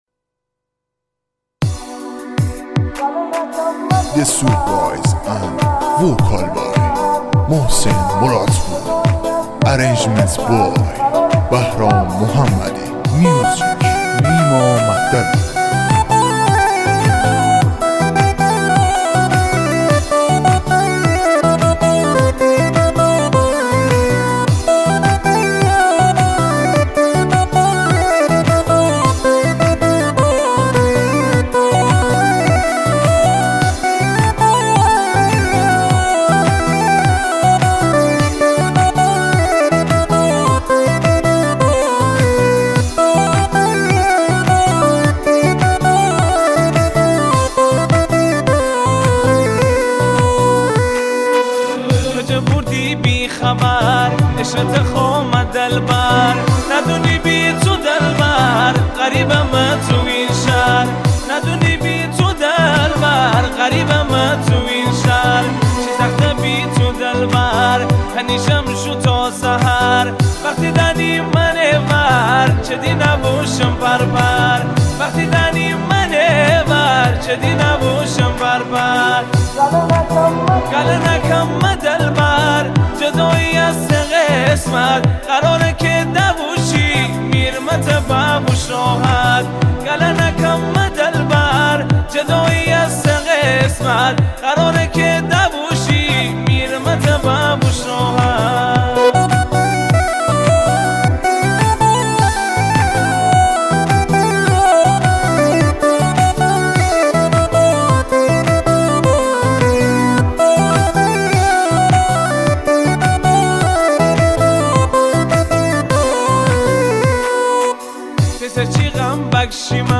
آهنگ مازندرانی
آهنگ شاد